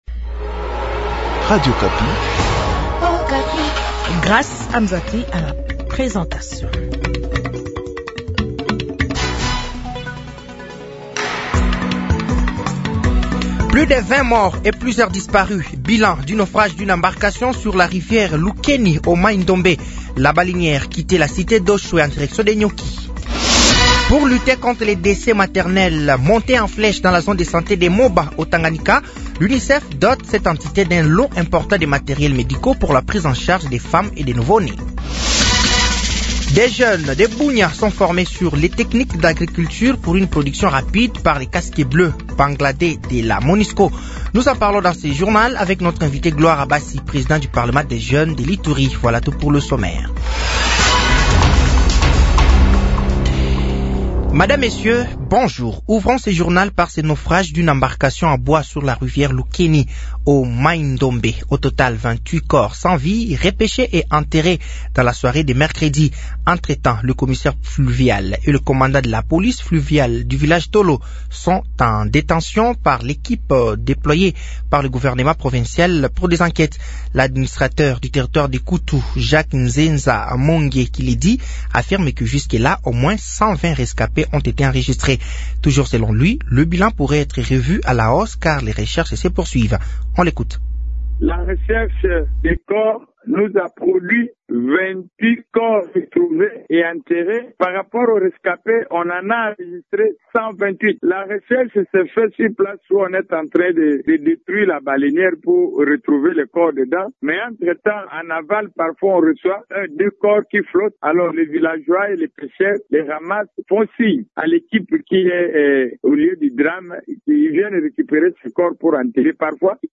Journal français de 15h de ce jeudi 22 août 2024